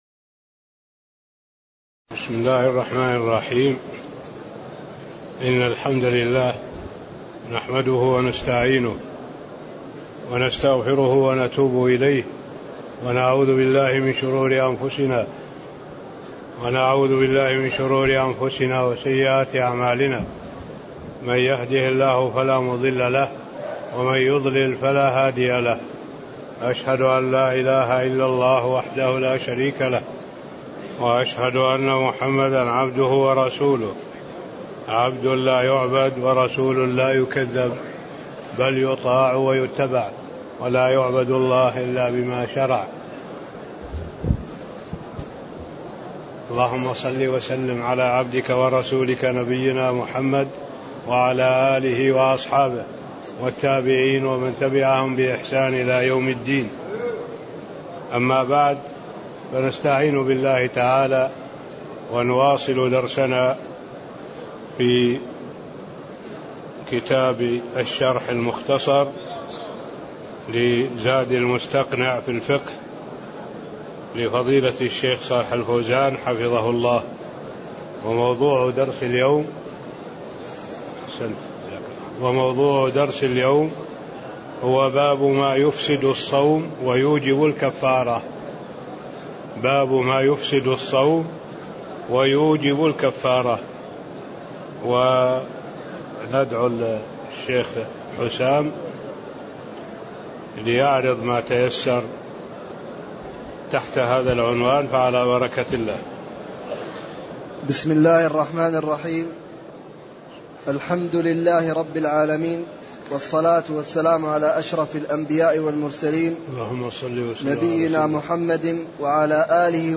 تاريخ النشر ٢٩ شعبان ١٤٣٤ هـ المكان: المسجد النبوي الشيخ: معالي الشيخ الدكتور صالح بن عبد الله العبود معالي الشيخ الدكتور صالح بن عبد الله العبود باب ما يفسد الصوم ويوجب الكفارة (08)